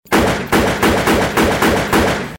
• REPETITIVE GUNSHOTS.wav
REPETITIVE_GUNSHOTS_tlJ.wav